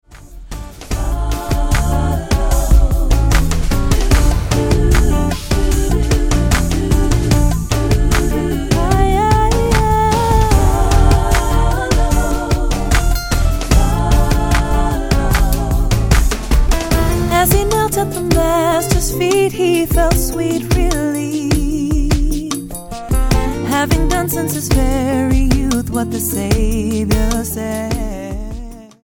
STYLE: Gospel
A delicious blend of R&B soul and latin rhythms